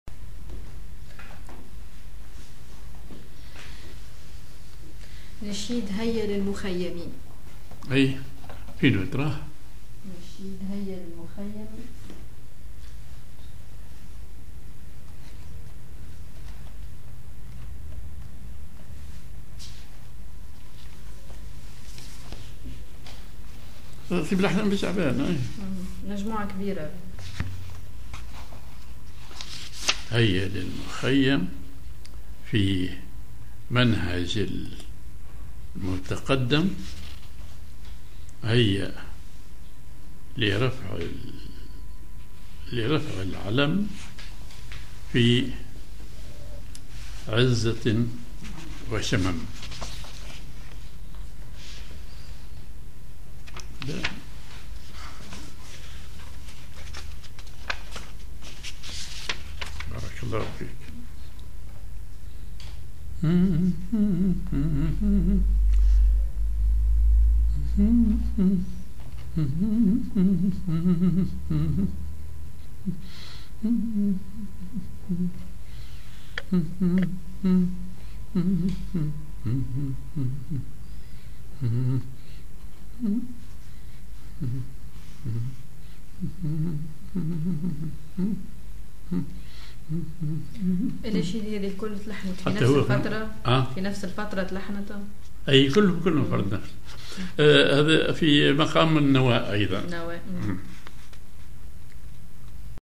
Maqam ar نوا
Rhythm ar ختم
genre نشيد